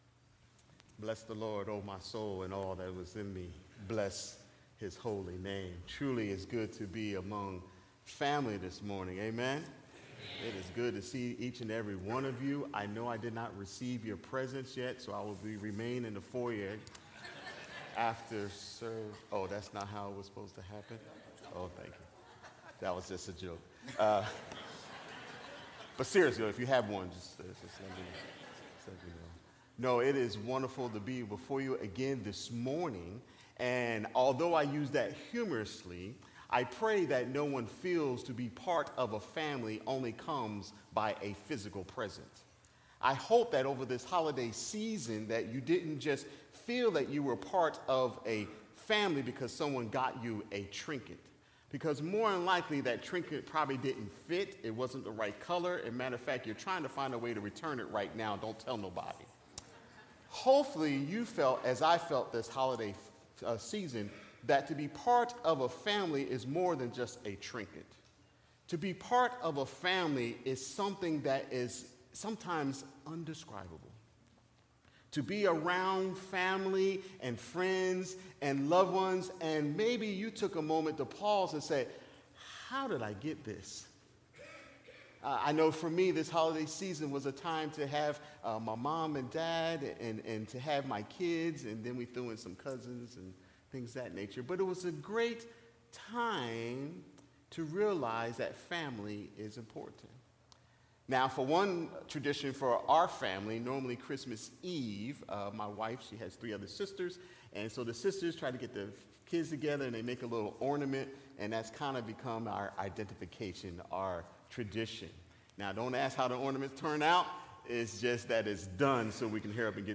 Sermon-1.12.20.mp3